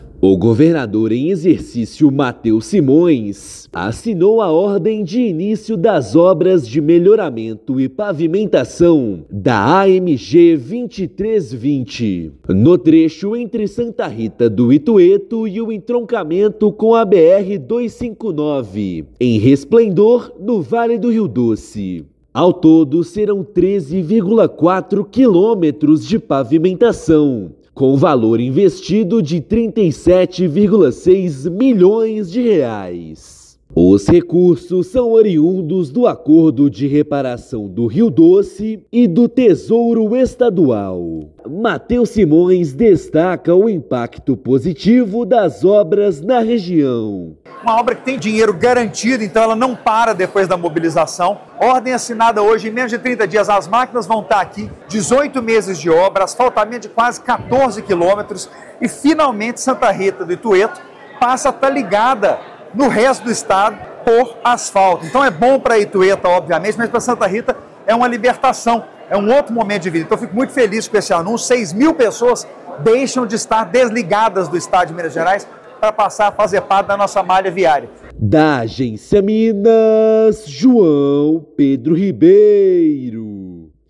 [RÁDIO] Governo de Minas assina ordem de início das obras de melhoramento e pavimentação da AMG-2320
Serão 13,4 quilômetros de pavimentação, com valor investido de R$ 37,6 milhões. Ouça matéria de rádio.